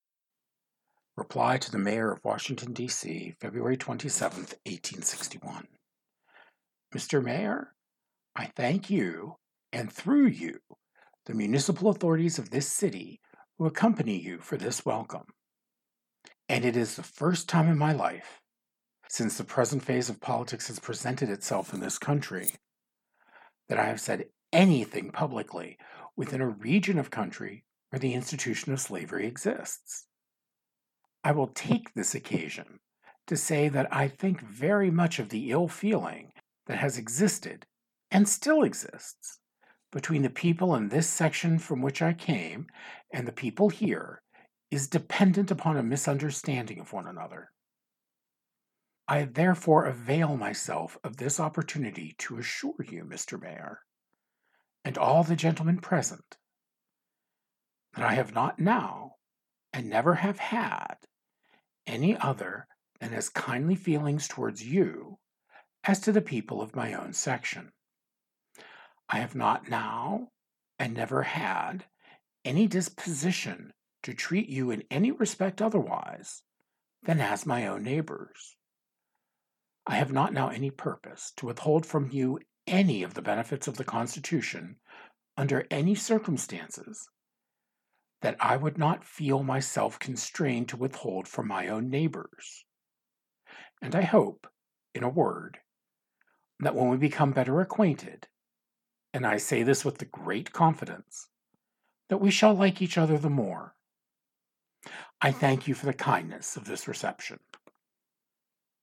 DC Speech